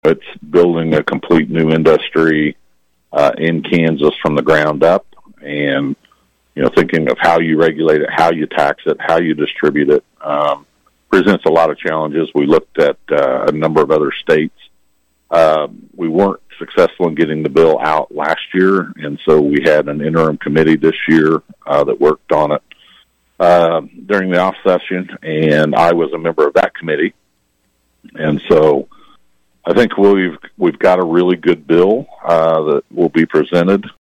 17th District Senator Jeff Longbine of Emporia was KVOE’s Newsmaker 2 guest Wednesday as KVOE continued its legislative previews.